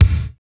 31.06 KICK.wav